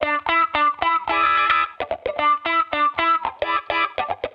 Index of /musicradar/sampled-funk-soul-samples/110bpm/Guitar
SSF_StratGuitarProc2_110E.wav